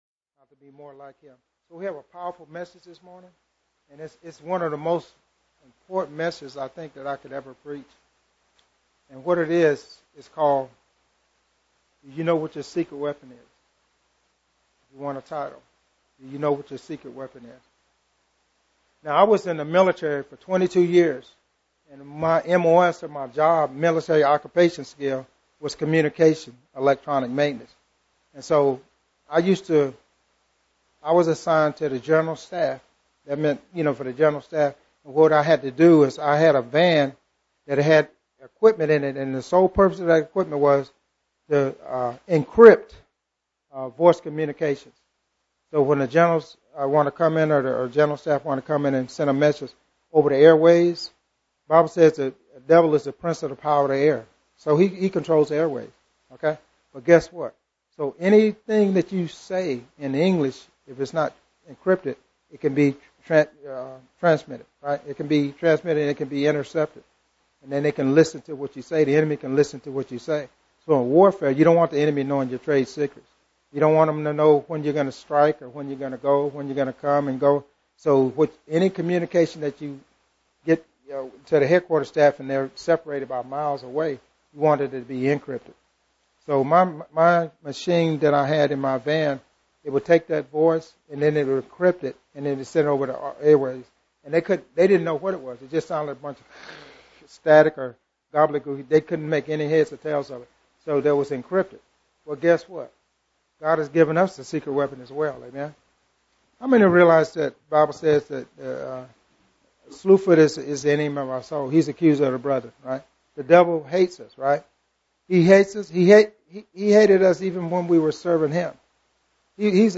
Genre: Sermons.